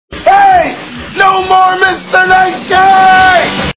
Pump Up The Volume Movie Sound Bites